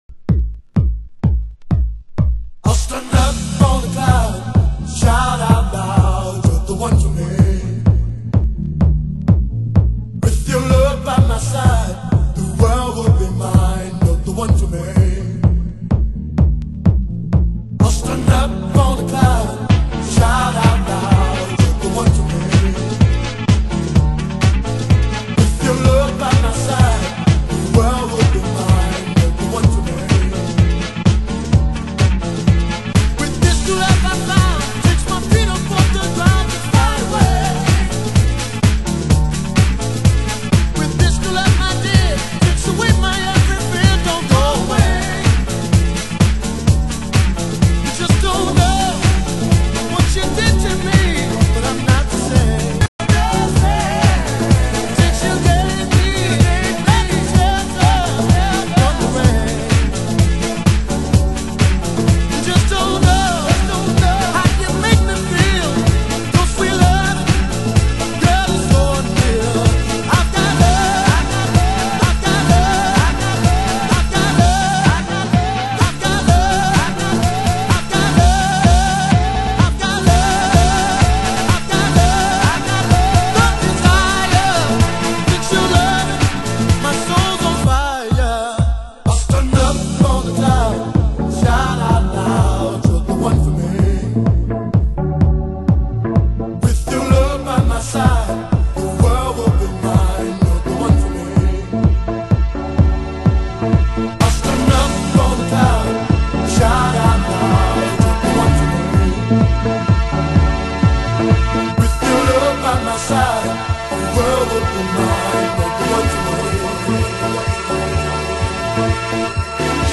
中盤 　　盤質：少しチリパチノイズ有